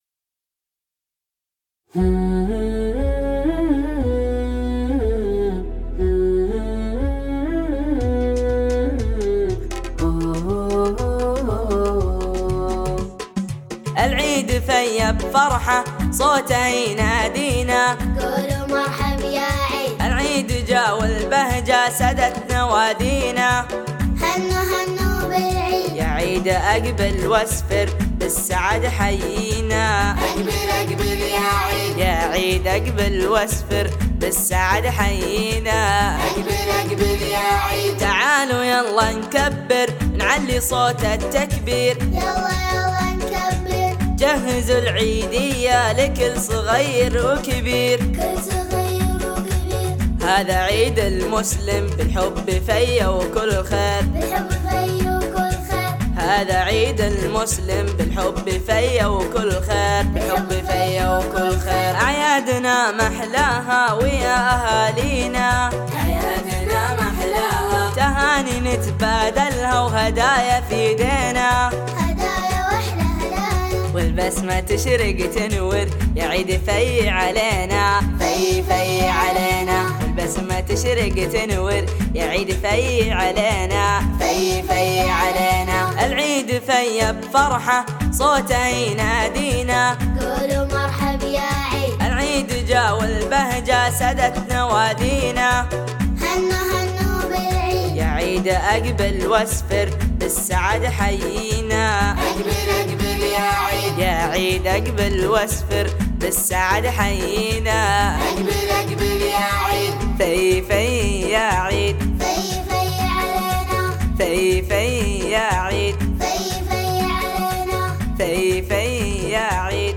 إيقاع